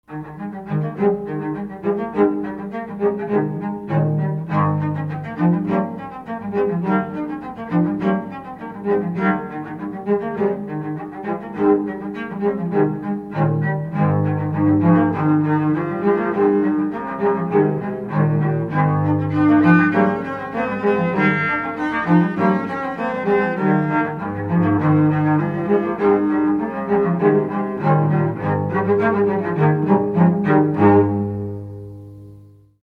Pièces pour 3 violoncelles